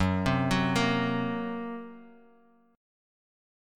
F#Mb5 chord